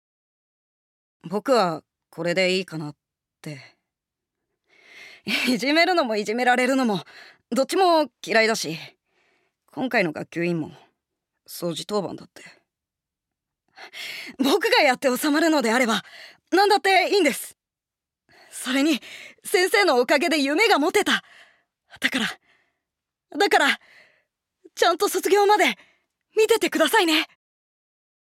◆根暗少年◆